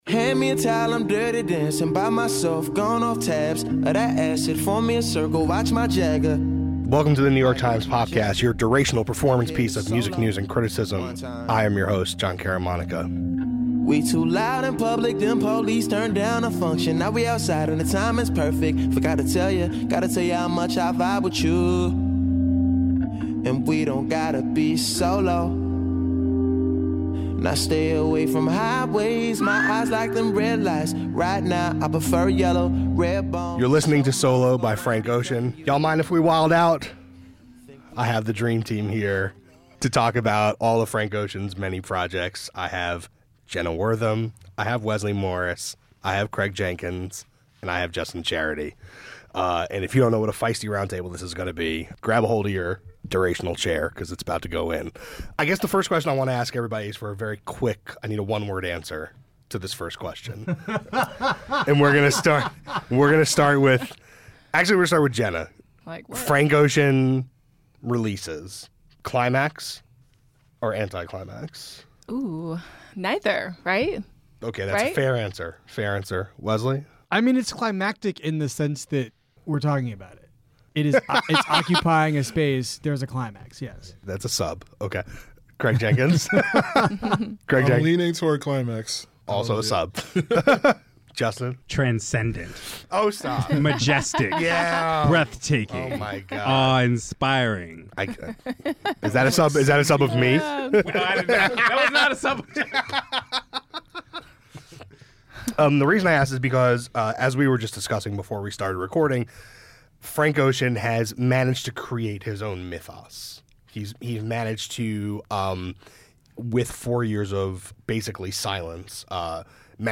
A roundtable discussion on the release of the musician’s latest works.